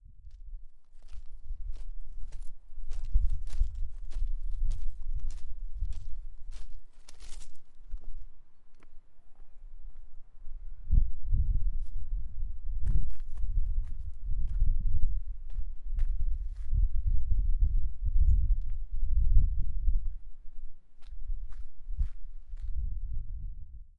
呼气2
描述：免费声音，效果人物呼吸音乐，efectoderespiración